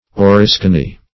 Oriskany \O*ris"ka*ny\, a. [From Oriskany, in New York.]